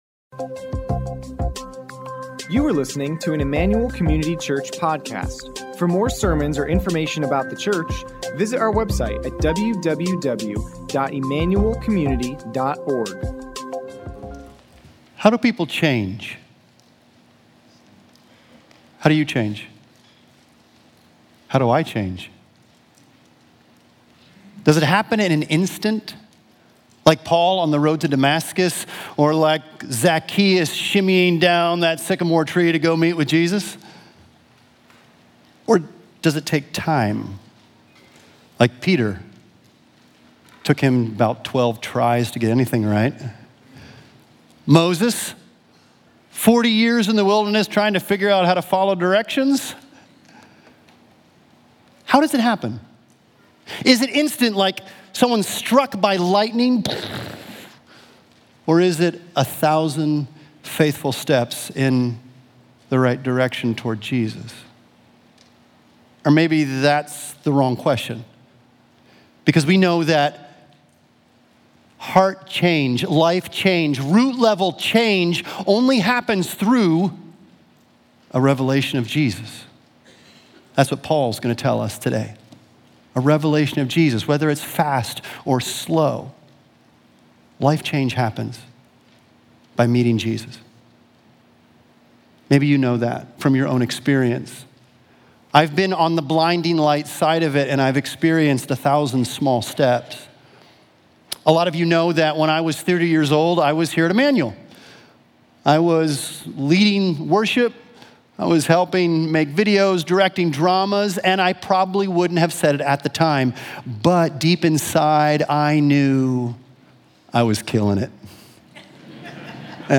Back To Series No Other Gospel - 2 Share Play Audio THIS WEEKEND, we’ll hear Paul’s own account from Galatians 1:10–24 and discover what happens when grace stops being an idea you work for… and becomes real. Ecard Sermon Notes Completed Notes